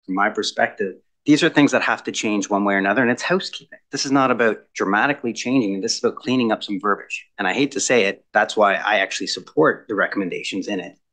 Sparks flew in Bluewater council chambers on Monday night (June 18) when councillors discussed the municipality’s alcohol policy.